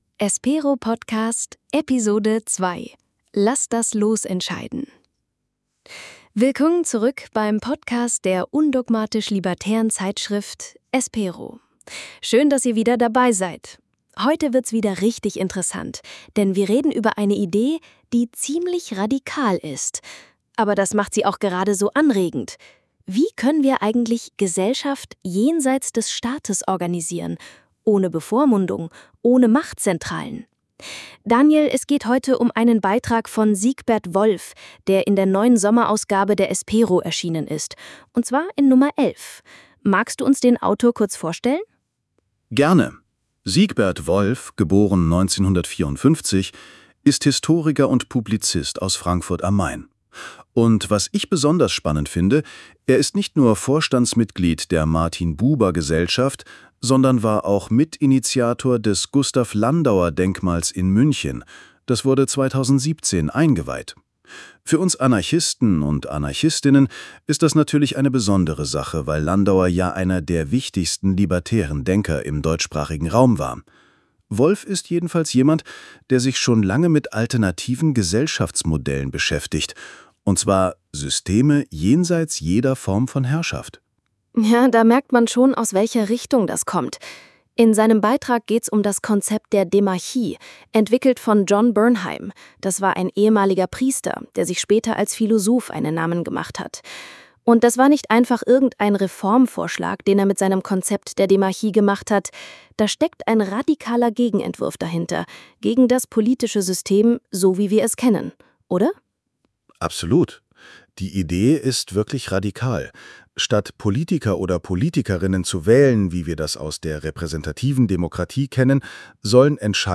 In locker geführten Gesprächen fassen wir zentrale Inhalte der Beiträge kompakt zusammen – verständlich und alltagstauglich.
Technisch wird der Podcast mithilfe von KI produziert.